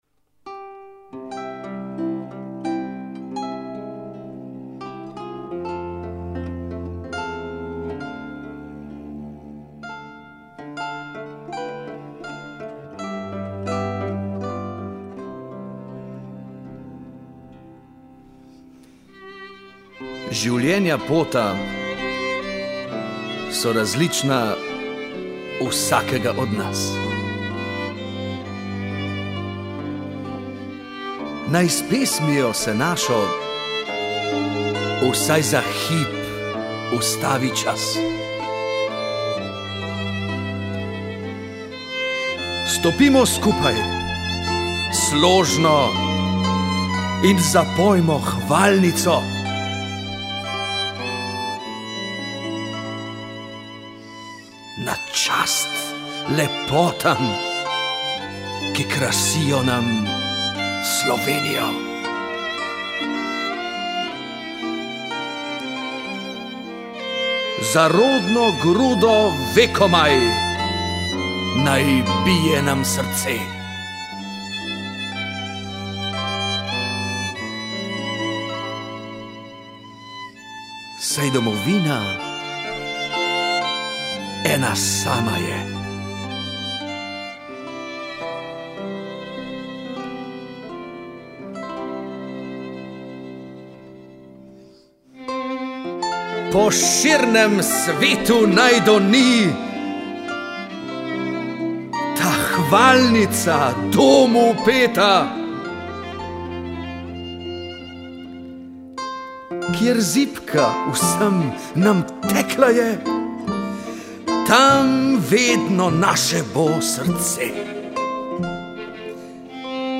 recitacija